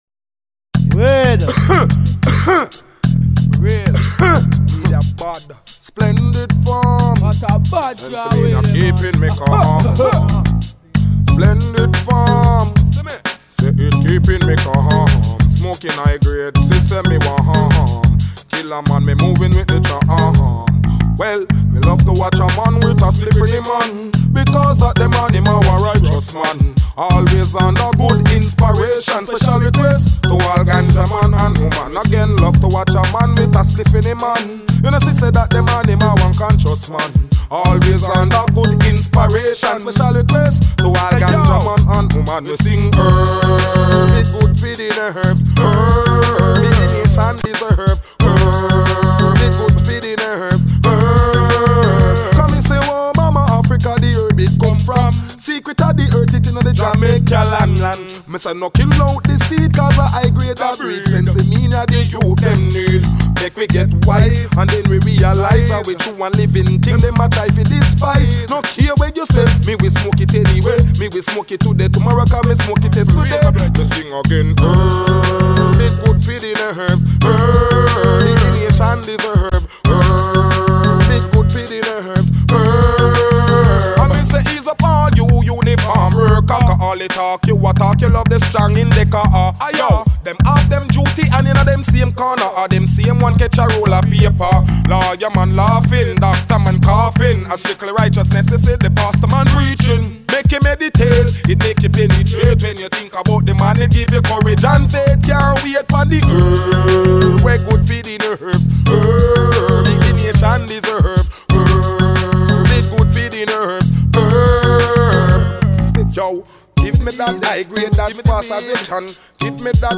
Rasta Music
Just some reggae.